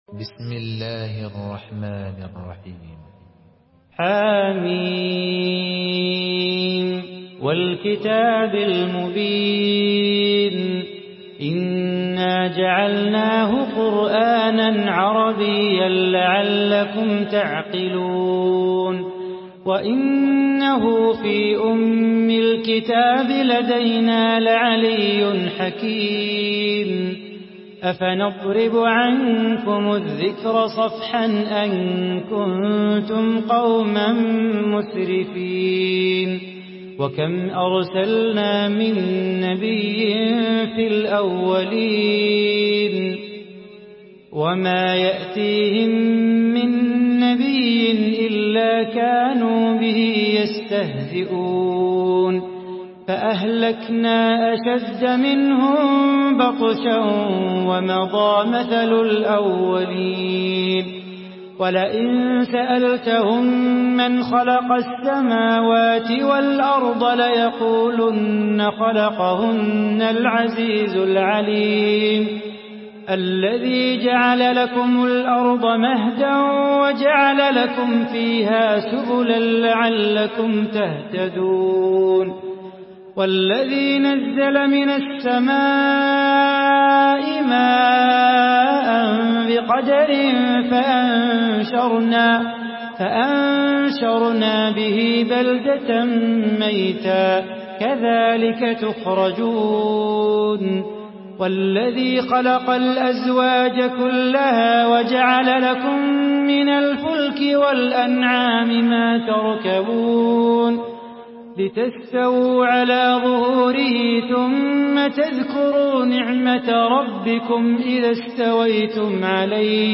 Surah Az-Zukhruf MP3 by Salah Bukhatir in Hafs An Asim narration.
Murattal